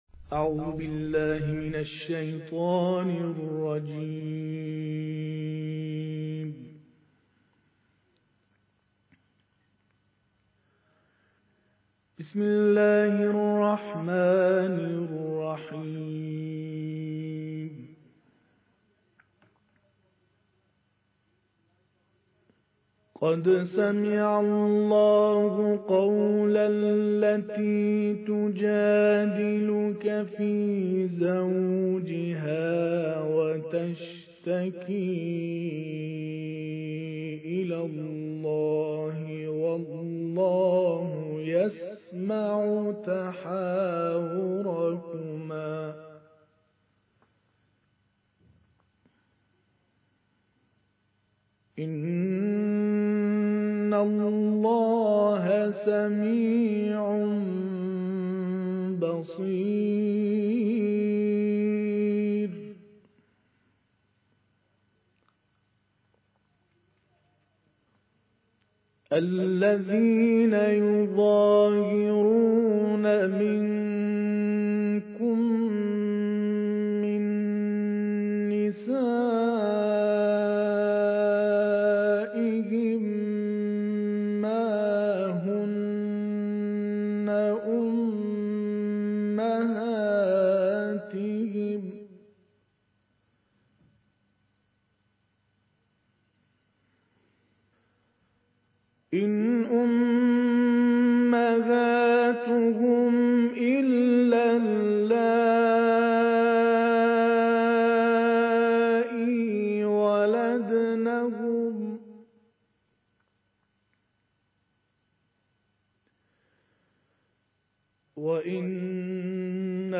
دانلود قرائت سوره مجادله ایات 1 تا 10